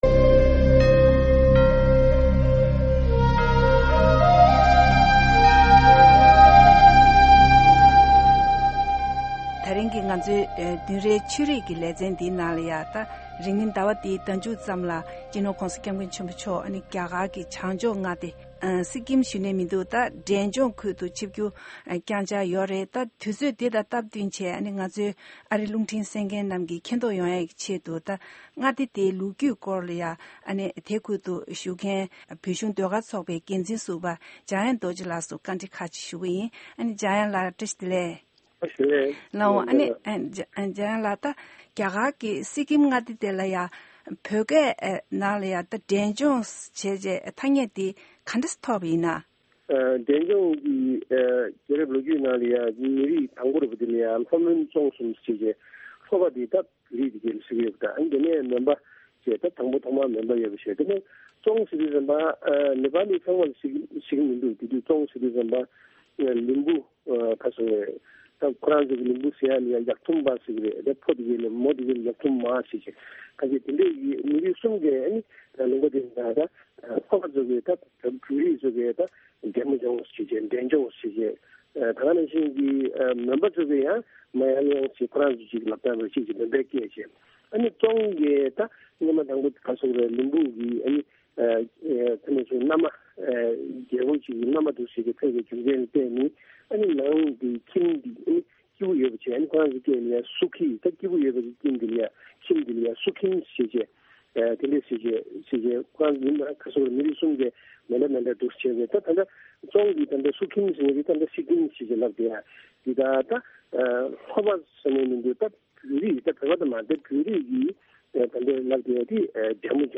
འབྲས་རྗོངས་མངའ་སྡེའི་སྐོར་གནས་འདྲི་ཞུས་པ་ཞིག་གསན་གྱི་རེད།